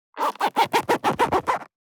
432ジッパー,チャックの音,洋服関係音,ジー,バリバリ,カチャ,ガチャ,シュッ,パチン,ギィ,カリ,カシャ,スー,
ジッパー効果音洋服関係